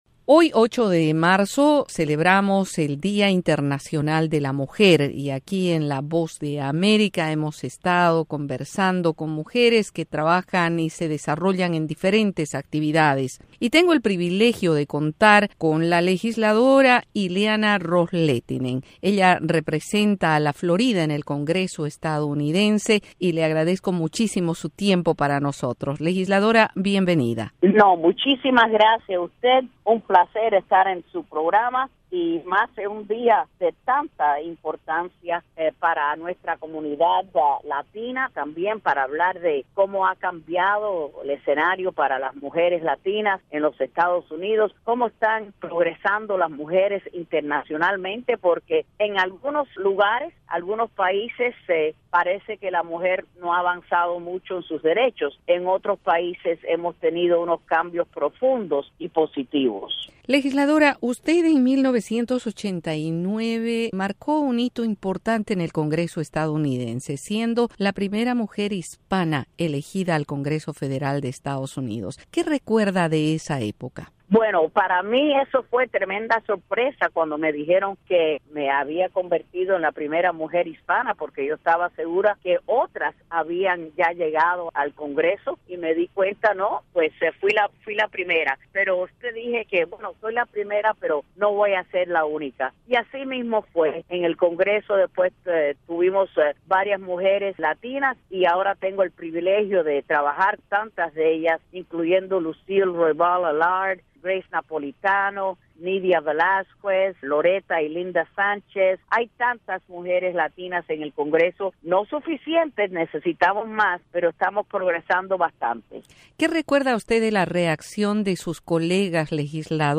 Entrevista a la congresista republicana Ileana Ros-Lehtinen